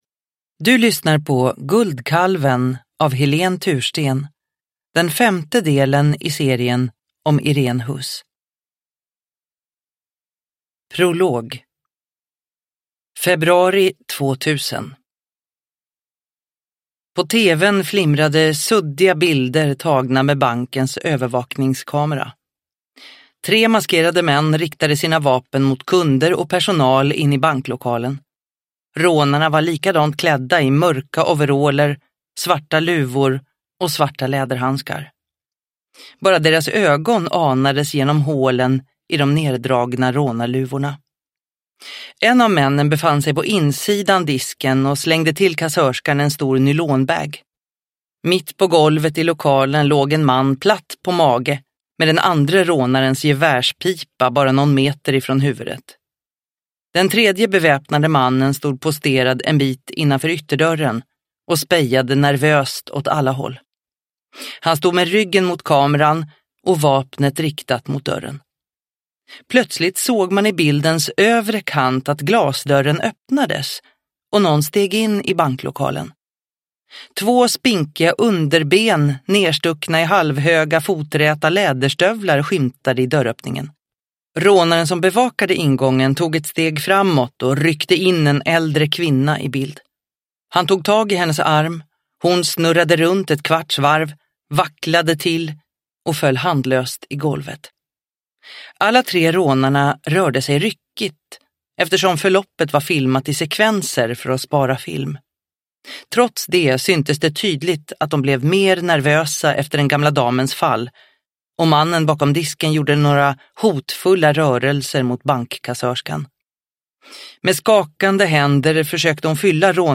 Guldkalven – Ljudbok – Laddas ner